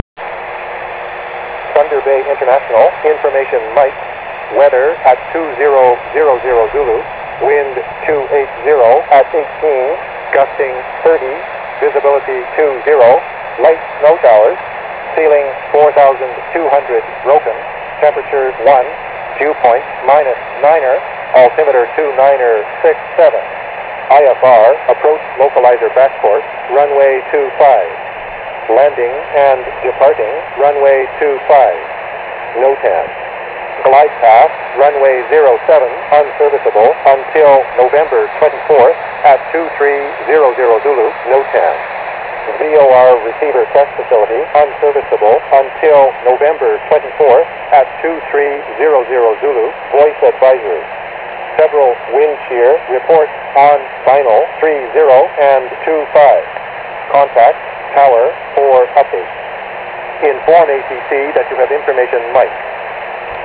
Thunder Bay Automatic Terminal Information System
대다수 중대형 공항에서는 컴퓨터가 여러가지 공항정보를 천천히 불러줍니다
위 내용은 컴퓨터가 천천히 불러주는 것이기 때문에 그리 어렵지 않게 들으실수 있습니다